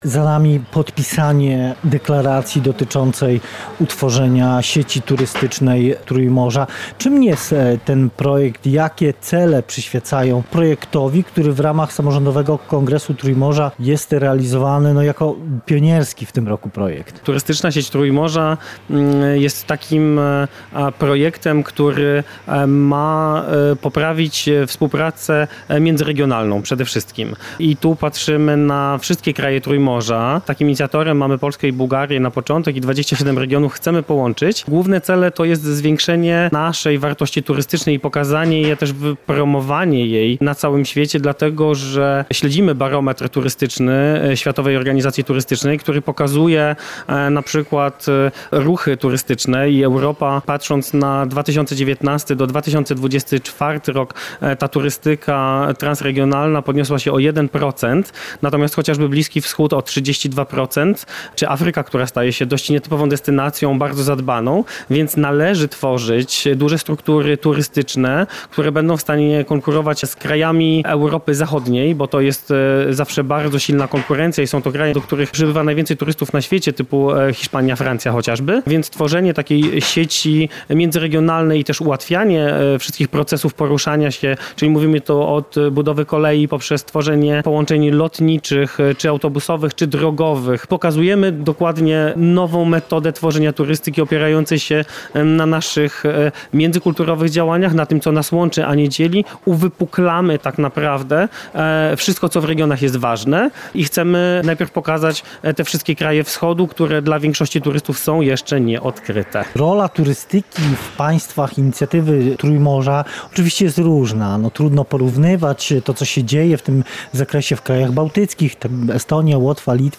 Za nami podpisanie deklaracji dotyczącej utworzenia sieci turystycznej Trójmorza.